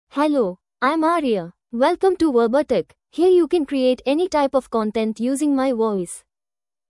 Aria — Female English (India) AI Voice | TTS, Voice Cloning & Video | Verbatik AI
Aria is a female AI voice for English (India).
Voice sample
Listen to Aria's female English voice.
Aria delivers clear pronunciation with authentic India English intonation, making your content sound professionally produced.